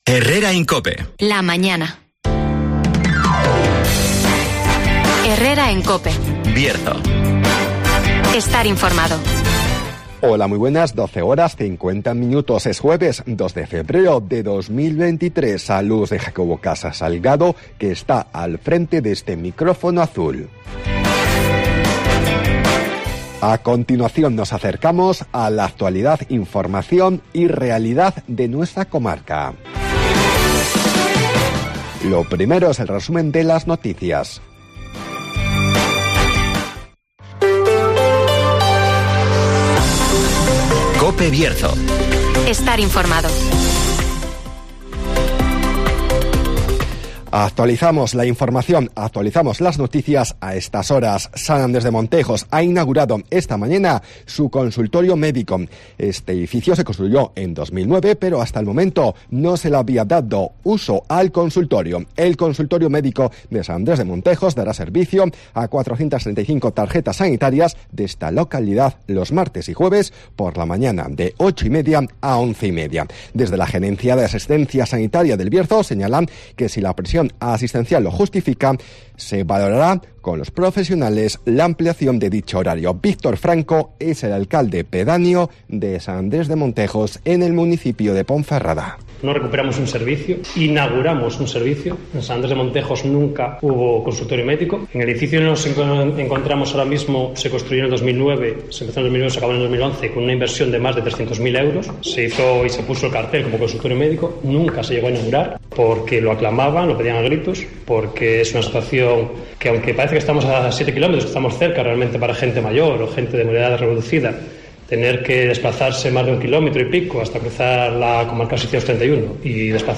Resumen de las noticias, el tiempo y la agenda.